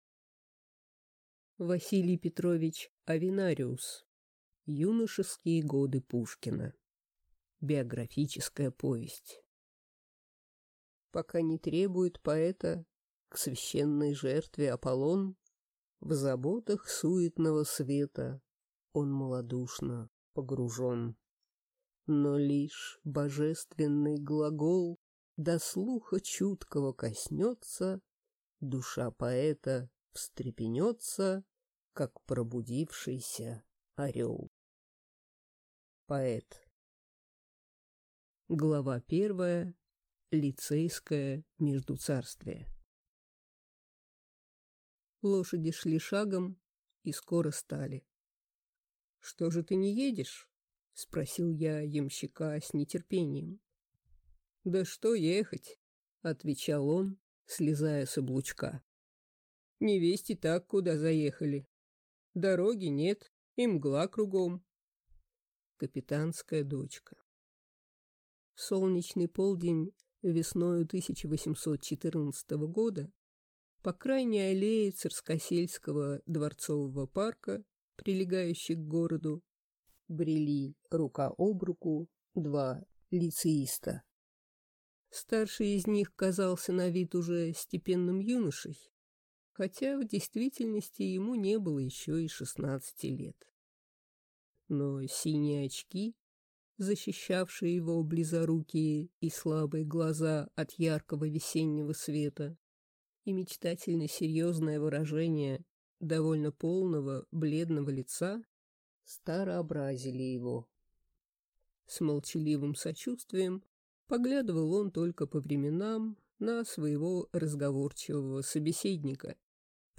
Аудиокнига Юношеские годы Пушкина | Библиотека аудиокниг